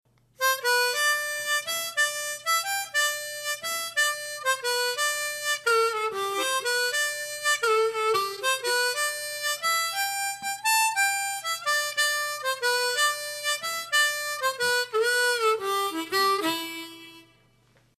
La base musical que encontrarás en la clase es en tonos MI menore y tocaremos una armónica en C tocando en quinta posición.